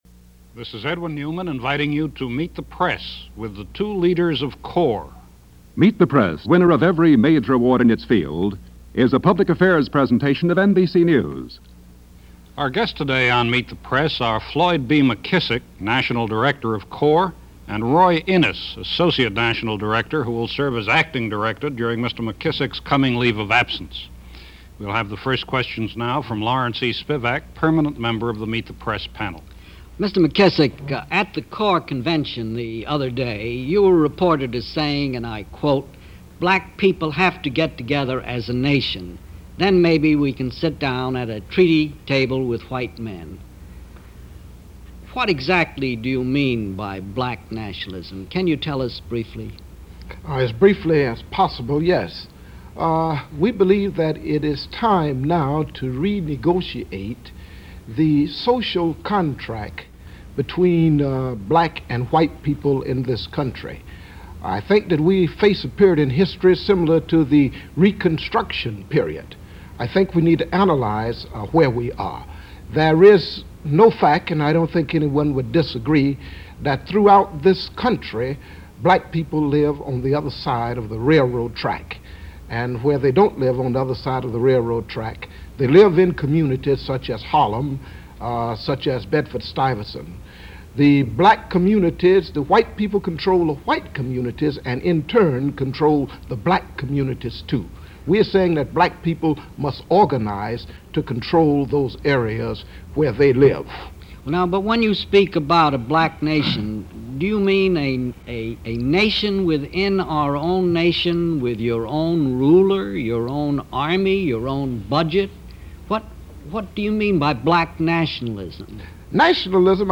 And so Meet The Press, on its broadcast of July 14, 1968 had principal members of CORE ( Congress Of Racial Equality ), Floyd McKissick and Roy Innes on for a panel discussion of where the issue of race and urban unrest stood, during that Summer of 1968. No holds barred, but an informative half-hour discussion that barely touched on the myriad problems of our cities and our society – but in 1968, any discussion of pressing issues such as these were important break-throughs. Here is that episode of Meet The Press – as it aired on July 14, 1968.